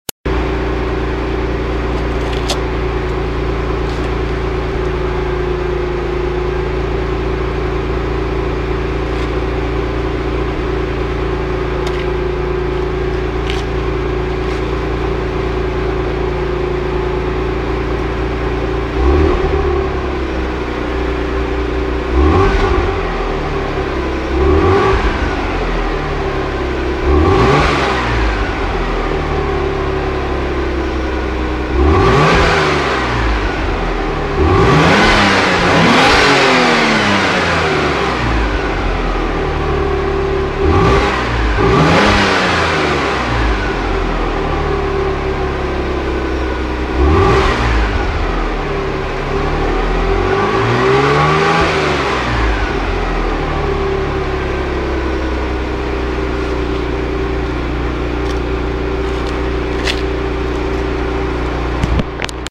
Guess the car sound
Car sound #1.mp3